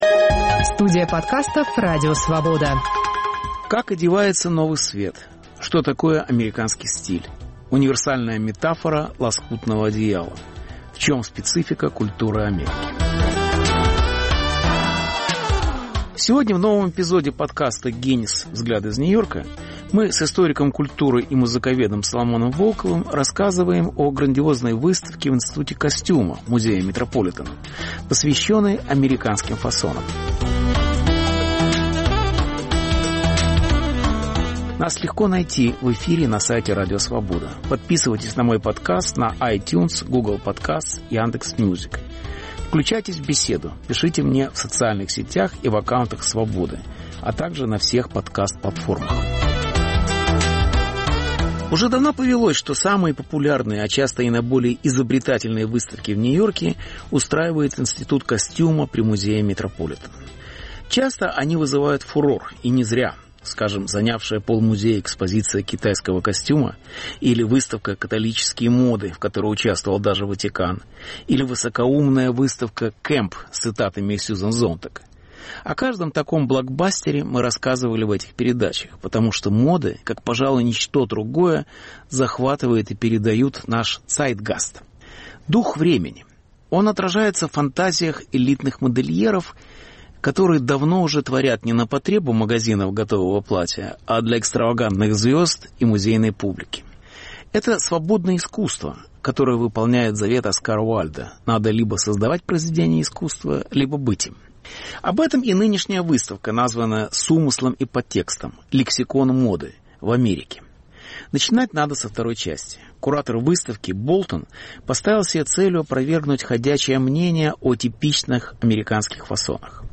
Беседа с Соломоном Волковым об американском стиле в моде - и в культуре. Повтор эфира от 04 октября 2021 года.